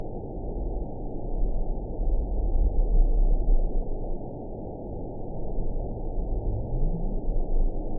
event 922920 date 05/06/25 time 23:44:55 GMT (1 month, 1 week ago) score 8.78 location TSS-AB10 detected by nrw target species NRW annotations +NRW Spectrogram: Frequency (kHz) vs. Time (s) audio not available .wav